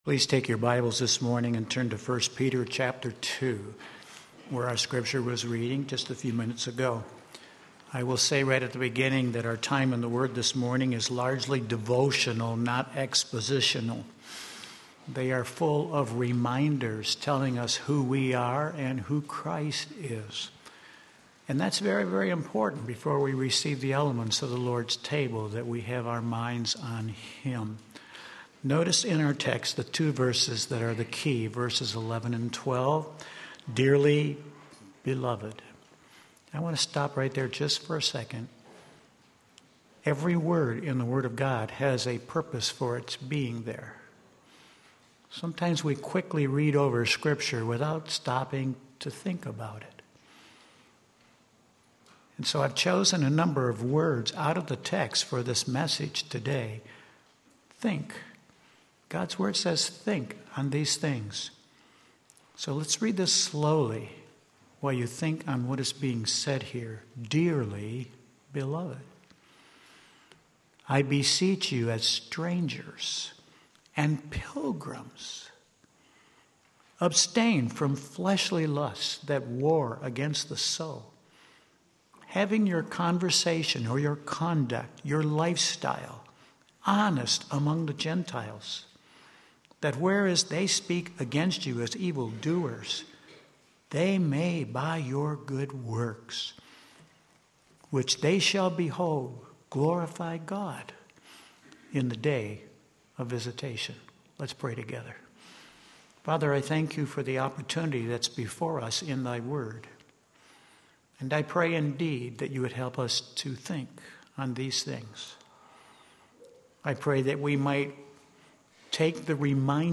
Sermon Link
Distinctive Christianity 1 Peter 2 Sunday Morning Service